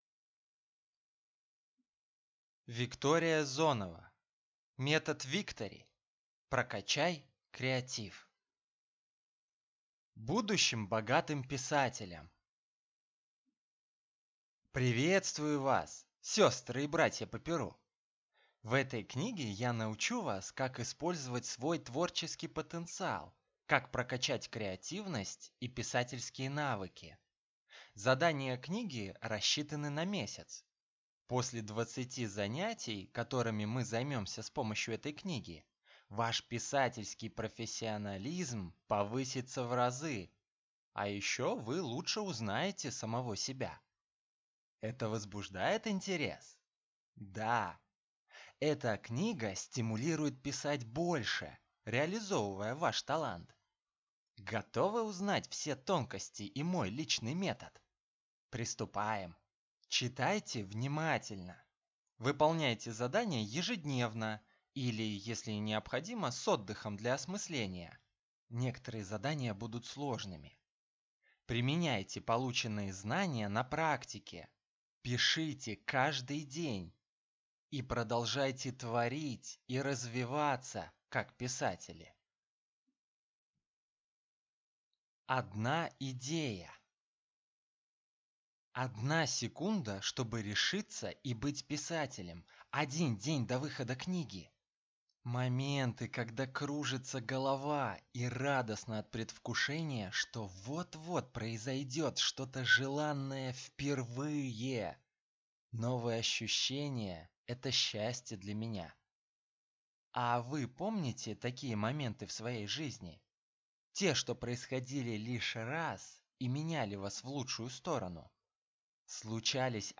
Аудиокнига Метод Victory. Прокачай креатив | Библиотека аудиокниг